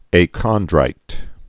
(ā-kŏndrīt)